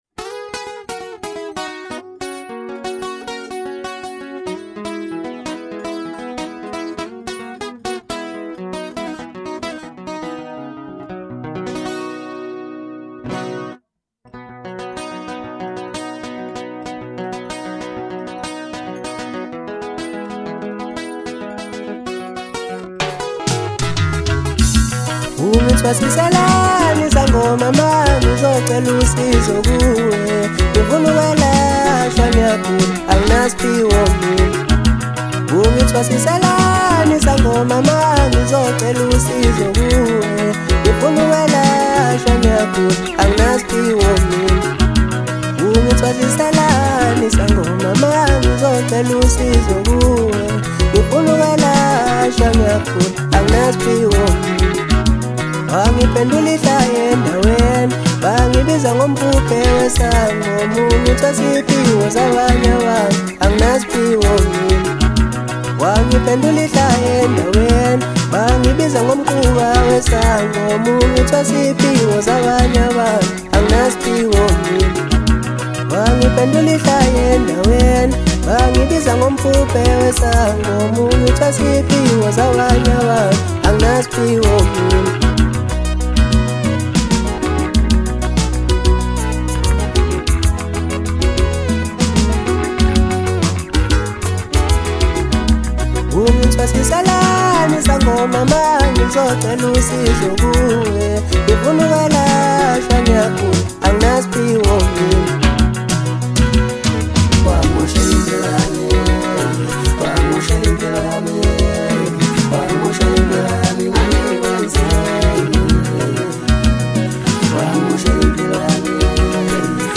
Home » Hip Hop » Latest Mix » Maskandi